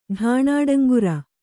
♪ ḍhānāḍaŋgura